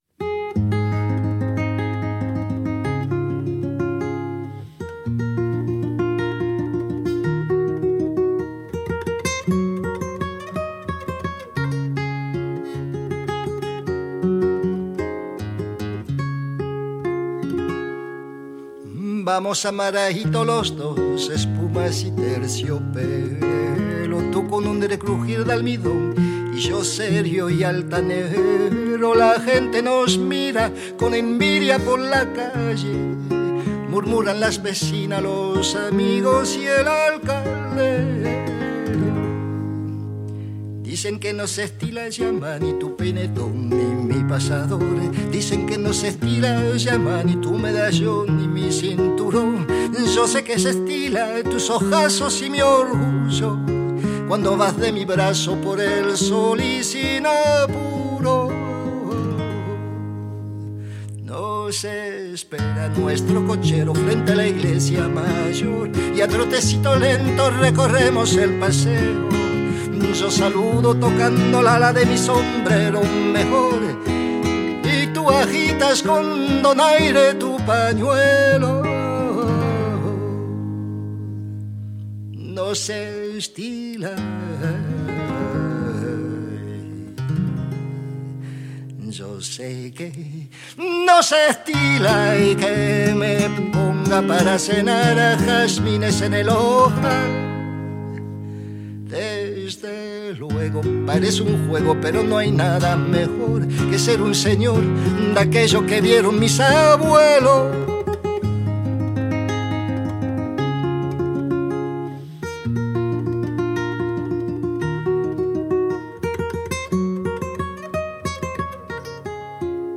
Decazeville, Zone du Centre, au Laminoir.